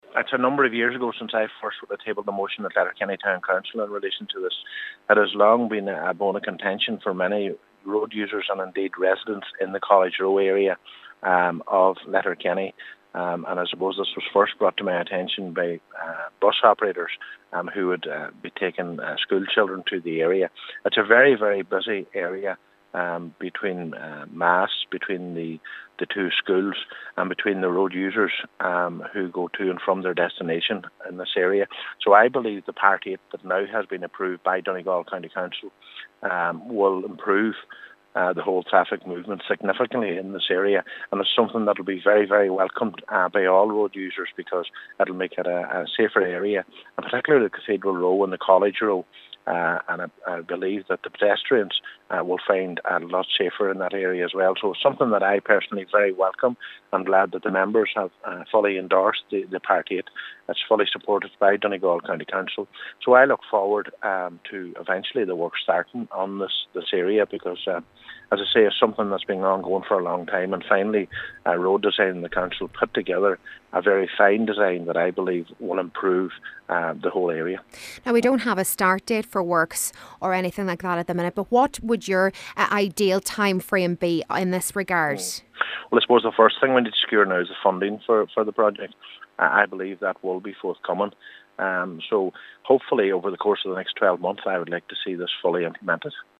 Local Cllr. Ciaran Brogan says the measures will greatly benefit both road users and residents in the area: